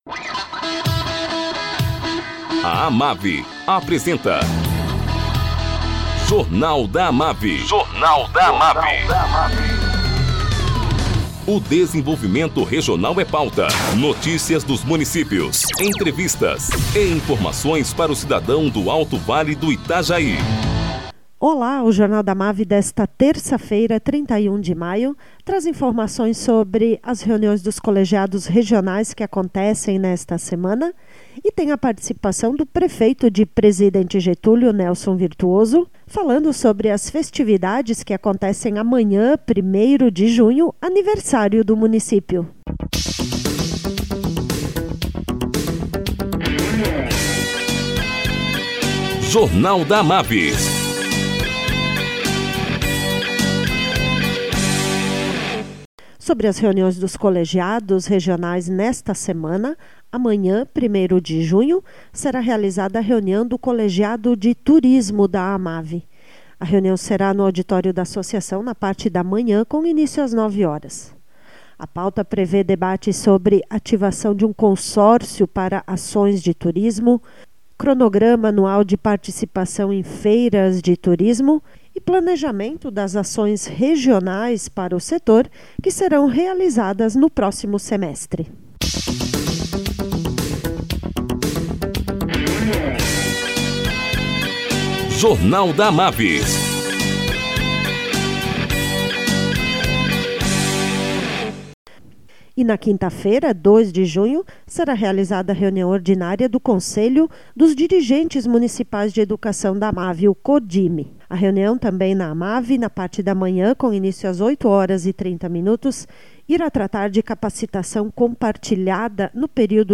Prefeito de Presidente Getúlio, Nelson Virtuoso, fala sobre a programação de amanhã, 1º de junho, aniversário do município.